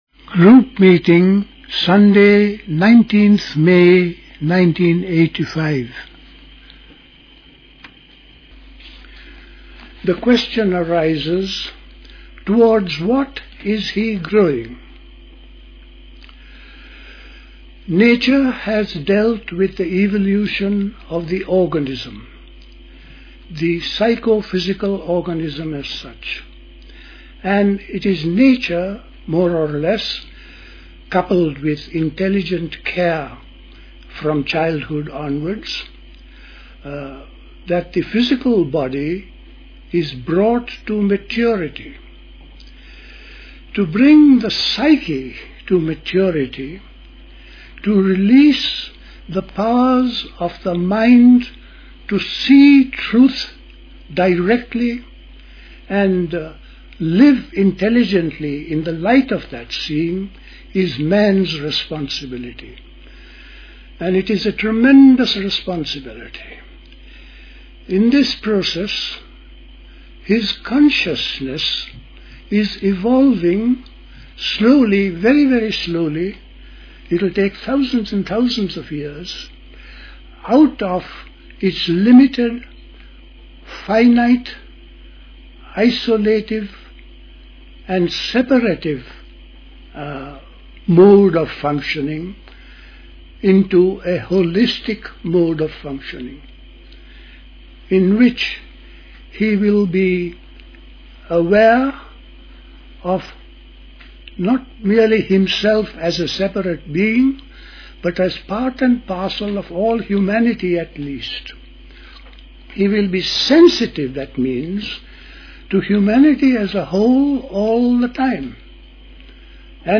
A talk
at Dilkusha, Forest Hill, London on 19th May 1985